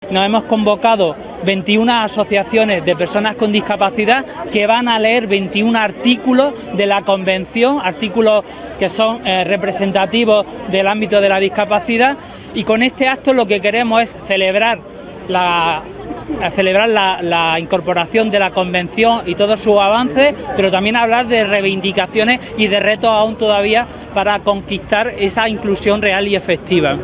El acto se ha desarrollado en la Avenida Federico García Lorca de la capital y ha contado con el conjunto de entidades de Almería que representan a las personas con discapacidad.